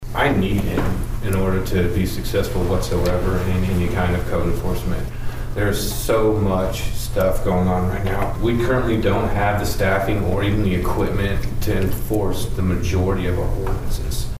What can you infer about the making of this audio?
A public hearing was held on Tuesday evening at Pawhuska's community center regarding the budget for the 2025-2026 fiscal year.